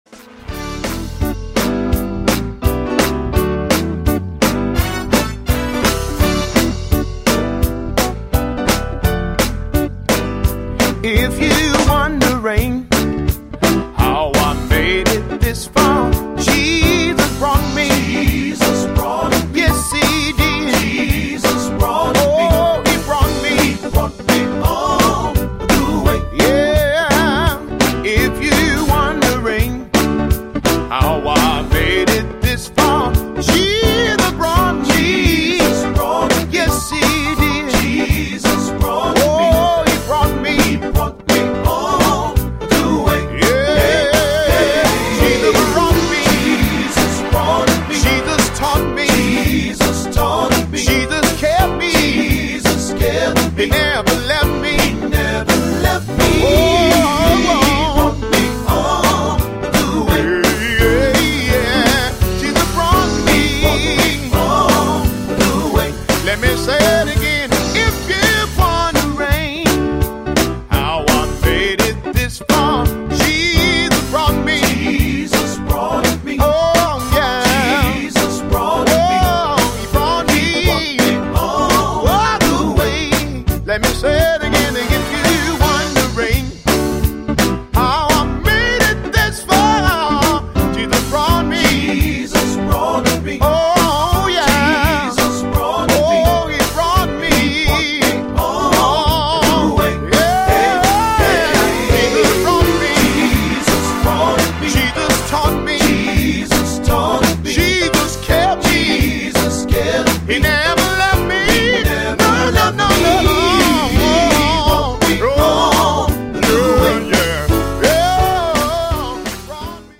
gospel music genre
upbeat
handling songs with an upbeat tempo